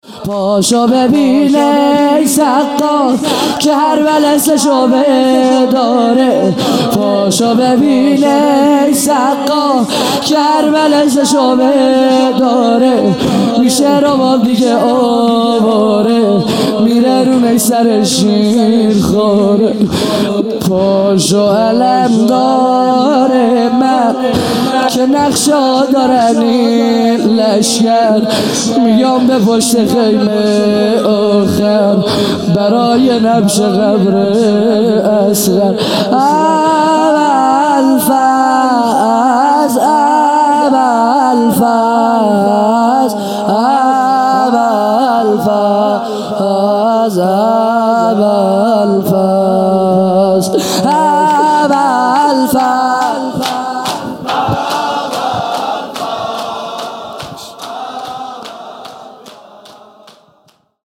خیمه گاه - هیئت بچه های فاطمه (س) - شور | پاشو ببین ای سقا
محرم 1441 | صبح نهم